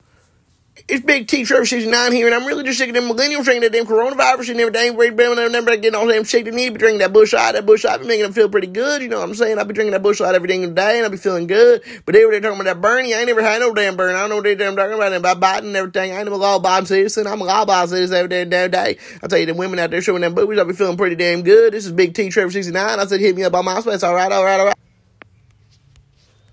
Recorded live at the iconic Helium Comedy Club in Indianapolis, this collection is packed with sharp one-liners, awkwardly relatable moments, and deep dives into his unique upbringing.
It’s stand-up comedy, but with a little more shtick, a lot more heart, and more than a few uncomfortable truths.